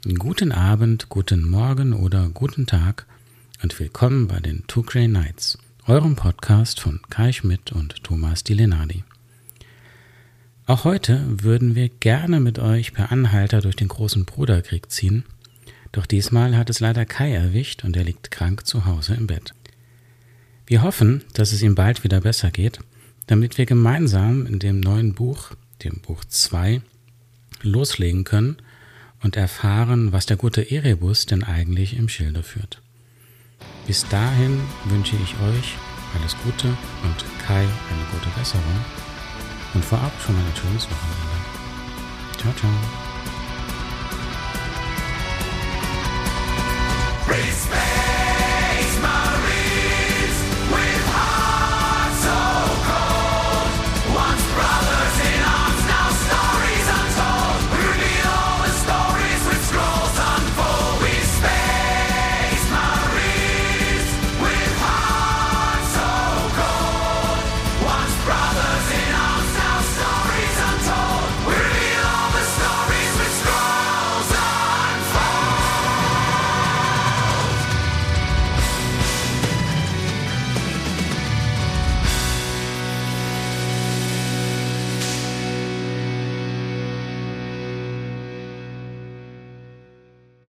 Wir möchten an dieser Stelle hervorheben, dass wir die Bücher nicht vorlesen. Wir fassen lediglich zusammen und besprechen unsere Eindrücke.